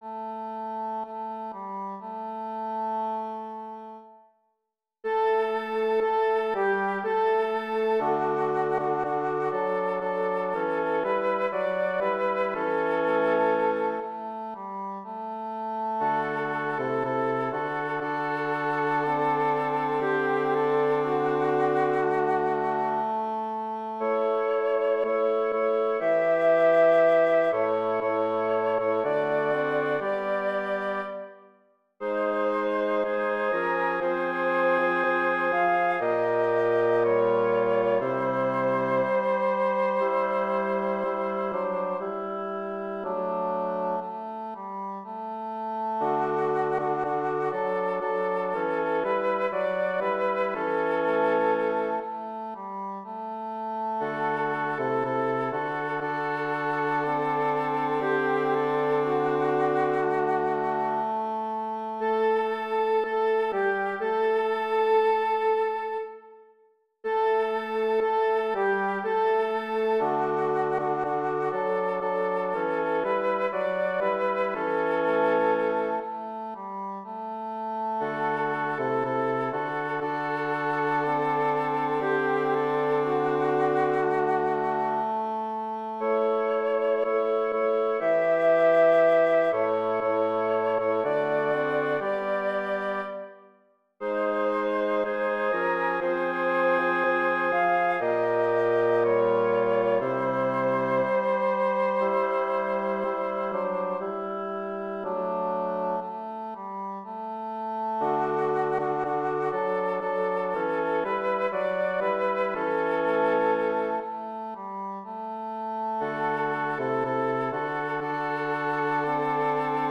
Voicing/Instrumentation: SATB
His music blends early music, 20th-century elements, and fundamentalist musical traditions